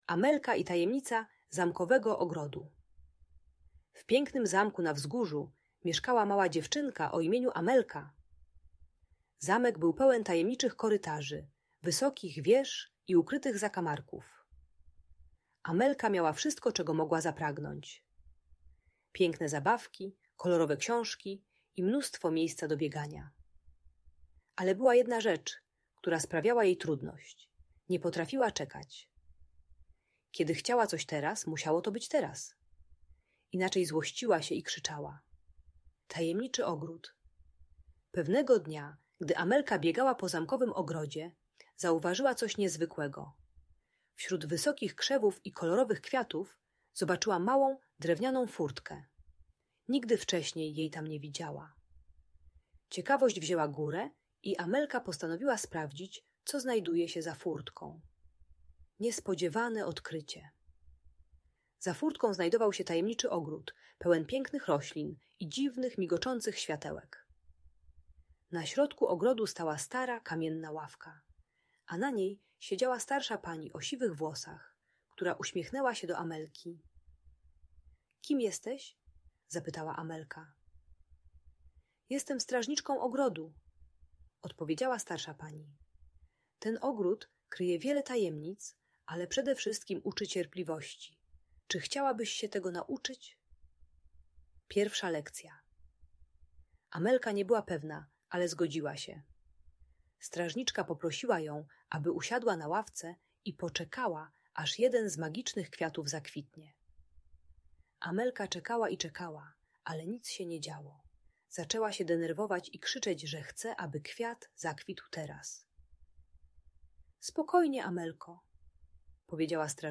Amelka i Tajemnica Zamkowego Ogrodu - Audiobajka